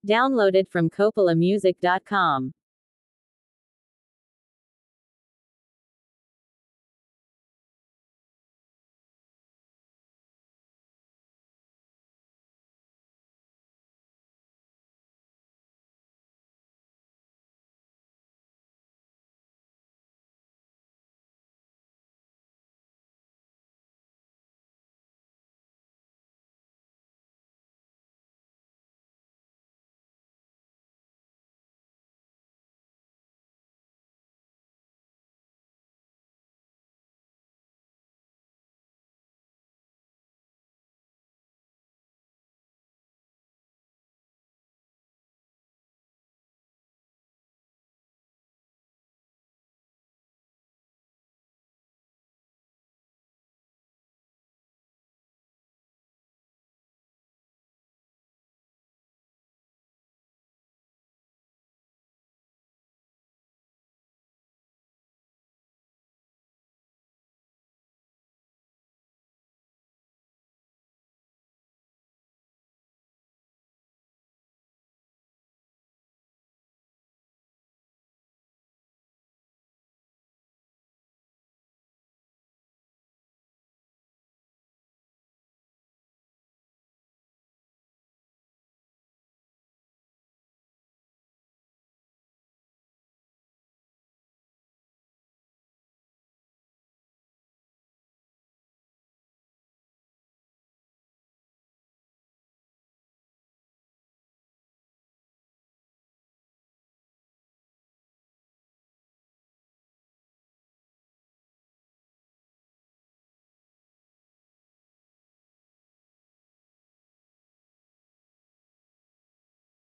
a smooth, heartfelt love anthem
a warm and melodic performance
an emotional and grounded verse
a feel-good, emotionally rich song